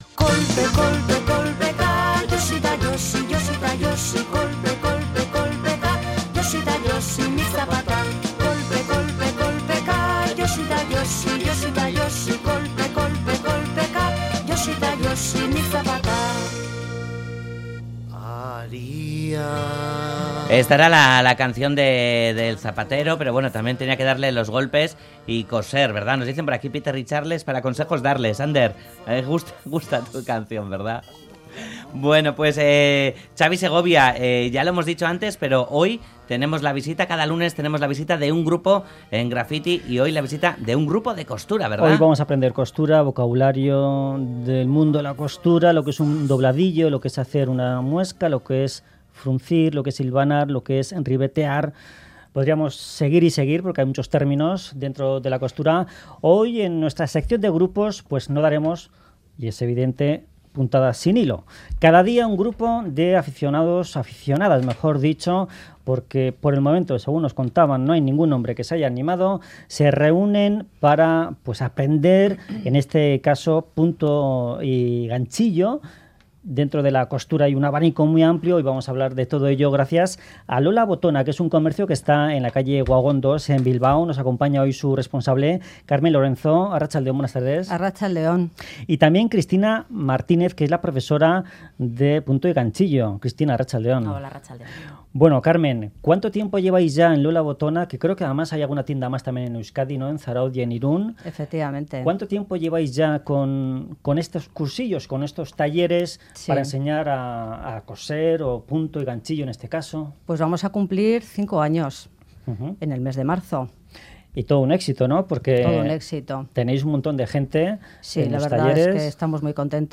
Audio: Alumnas de costura nos visitan para darnos las claves para hacer punto y ganchillo